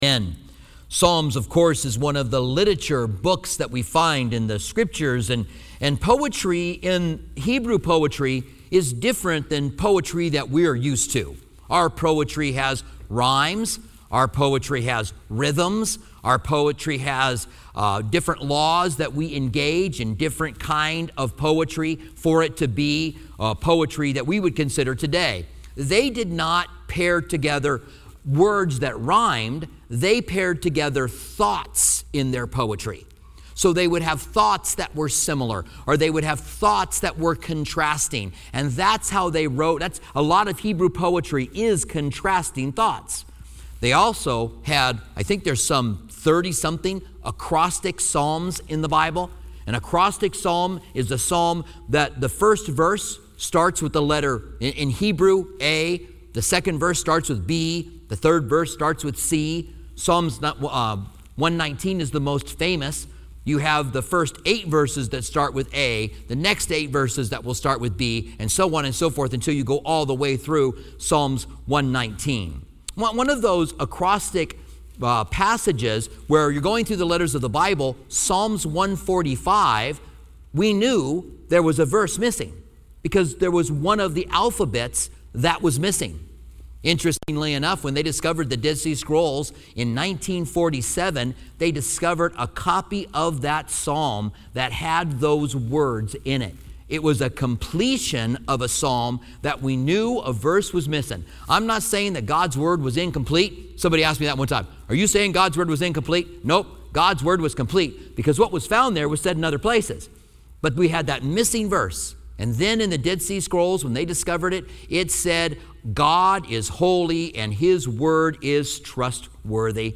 Commentary on Psalms